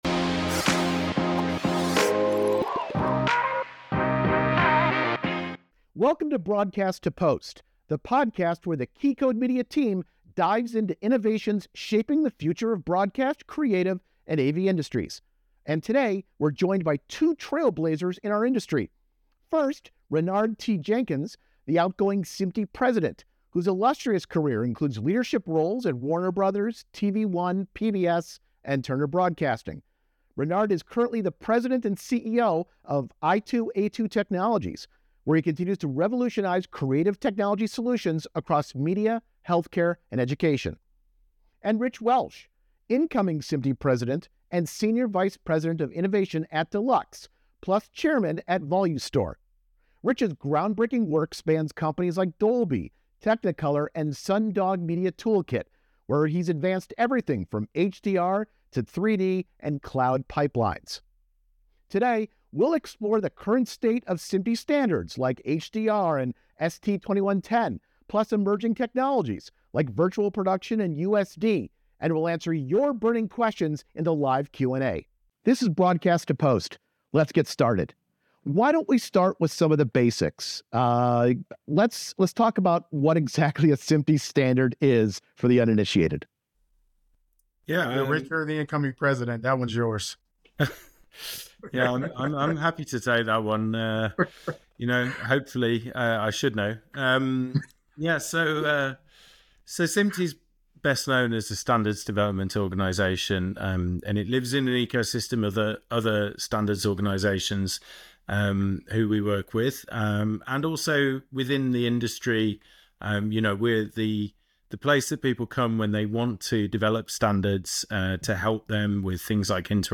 This technical conversation will explore the practical implications of ST 2110, Virtual Production, Universal Scene Description (USD), microservices, and more.